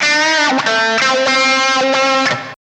134 GTR 5 -R.wav